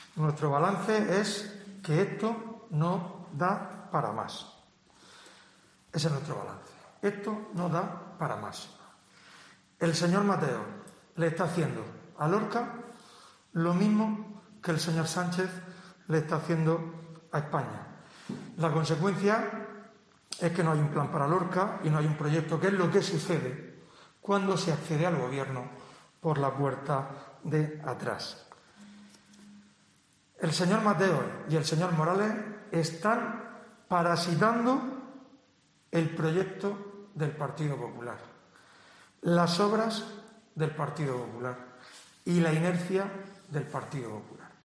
Fulgencio Gil, portavoz del PP